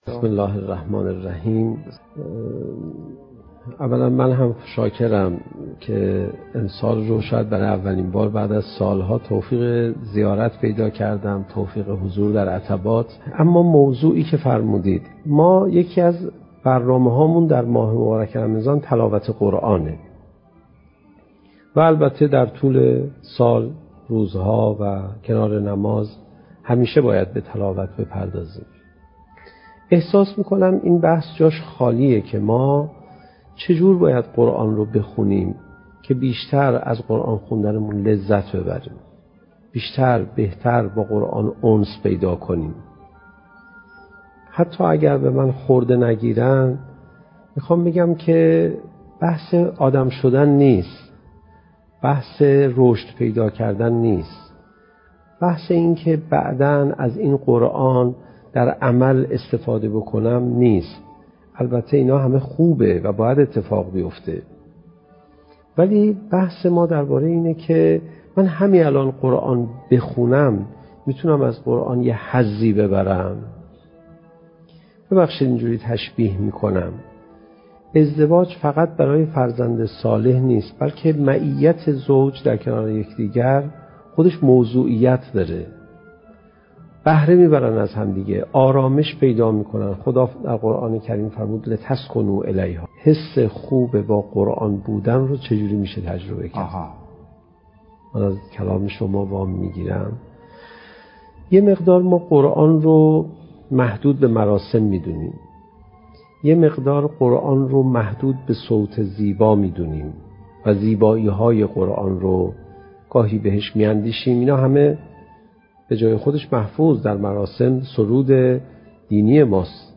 سخنرانی حجت الاسلام علیرضا پناهیان با موضوع "چگونه بهتر قرآن بخوانیم؟"؛ جلسه اول: "حق تلاوت قرآن"